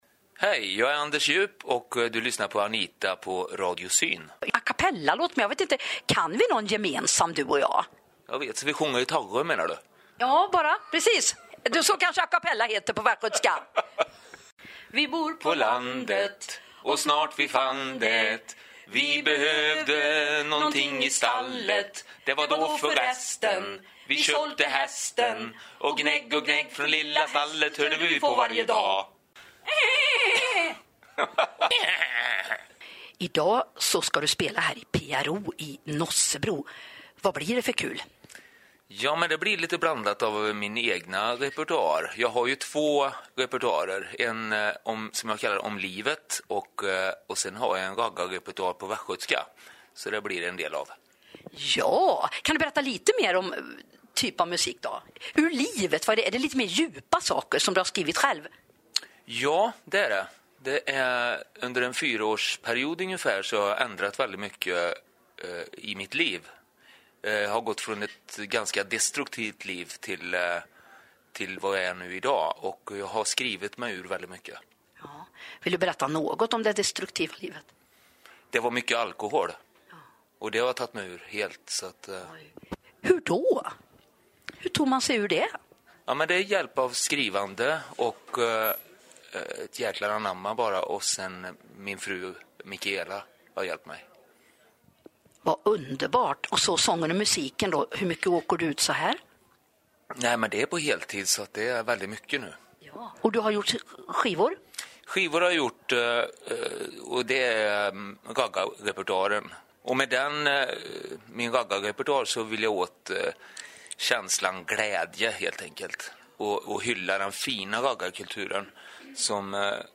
Kul intervju.